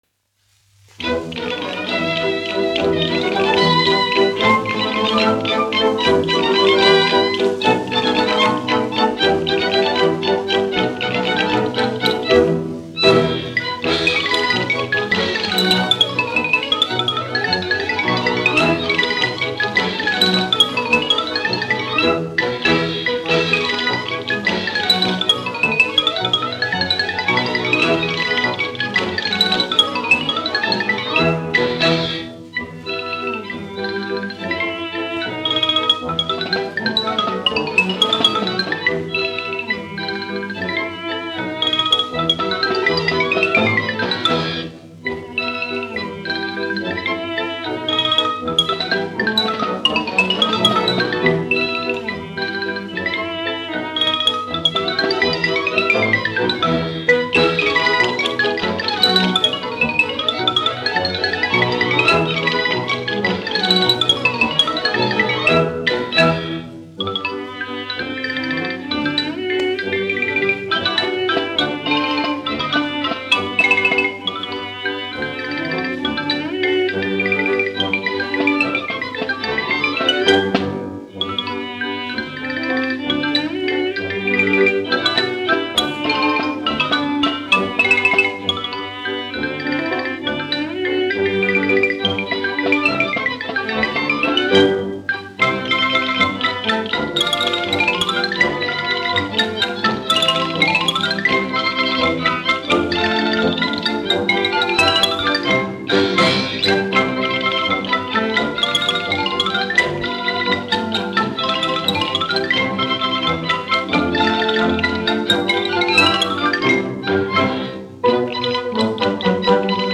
1 skpl. : analogs, 78 apgr/min, mono ; 25 cm
Ksilofons ar orķestri
Skaņuplate